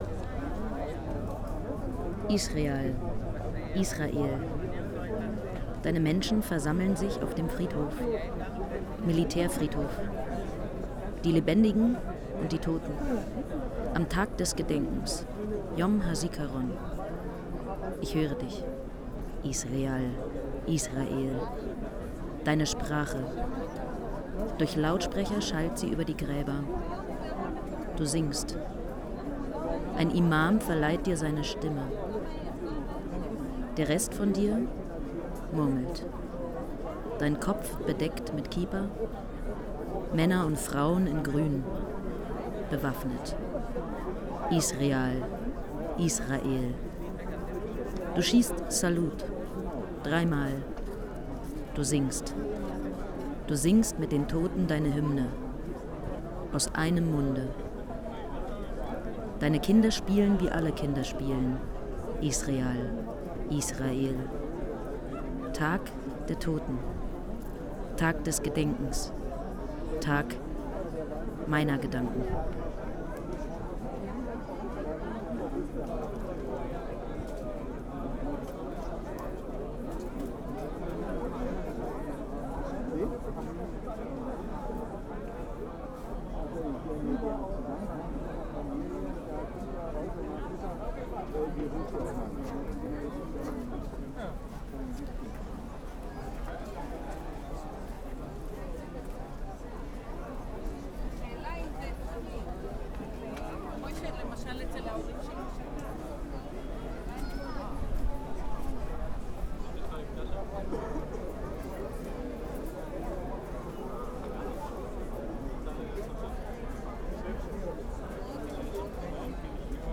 Meine Begegnungen mit dem Land halte ich in kleinen Hör- und Erzählspielen mit dem konkreten Ort fest.
Israel hören.
Militärfriedhof Haifa:
haifa-militaerfriedhof_1.wav